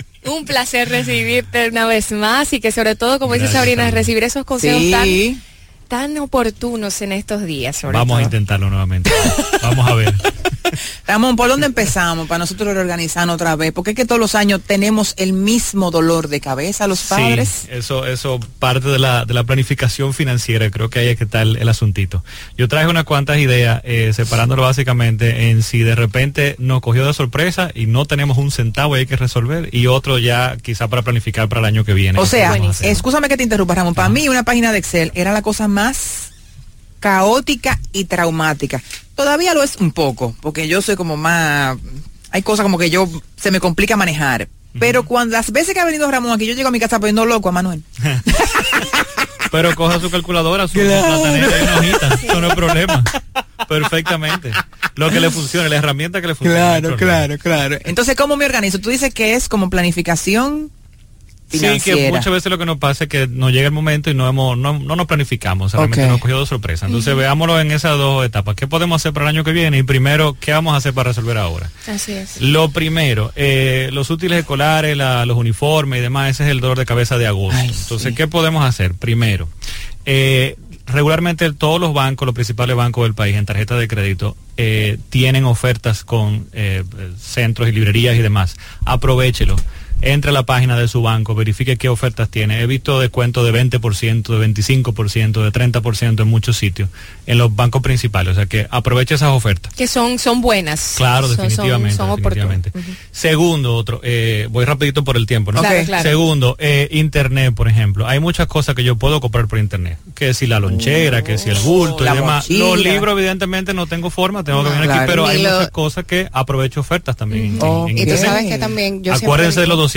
En esta participación en el programa Mujer Moderna Radio, que se transmite por Fidelity 94.1fm, compartimos algunas ideas para poder afrontar los gastos de los útiles escolares en este mes de agosto, así como algunas otras para prepararnos para el año próximo.